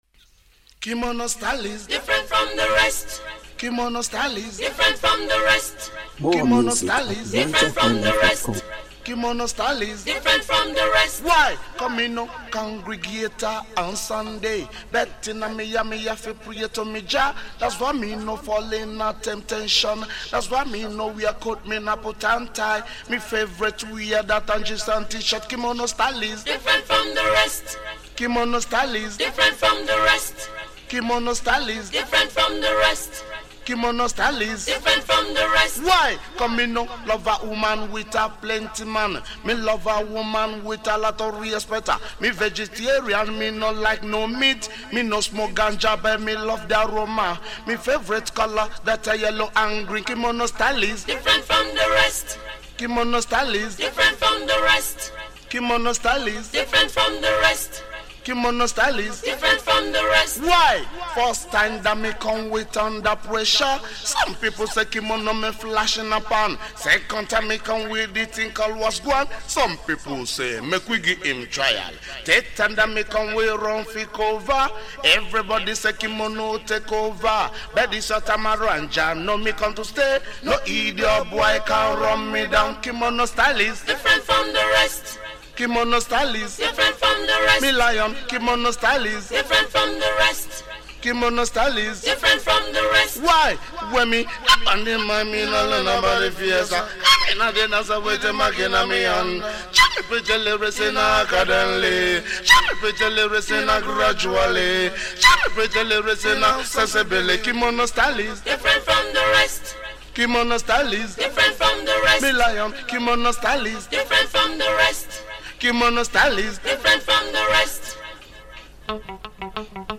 Home » Ragae
Nigerian Reggae Music